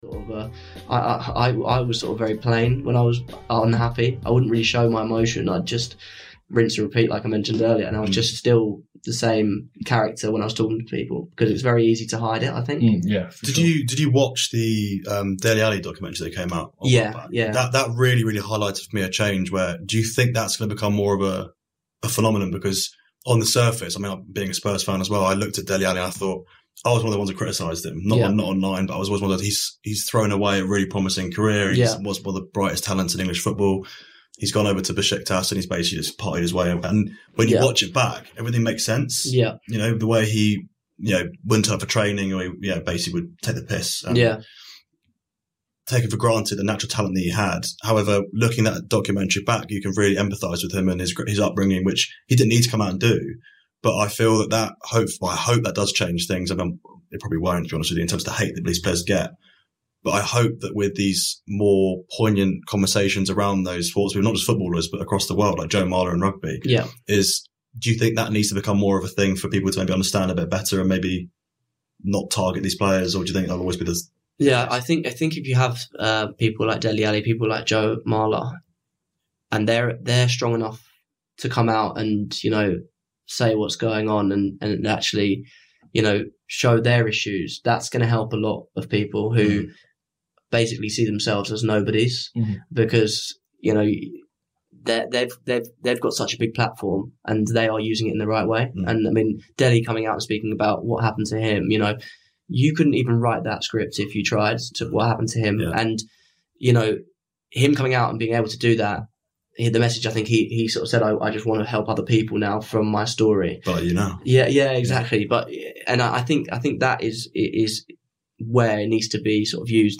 The conversation shines a light on the silent pressures shouldered by the youth in professional sports.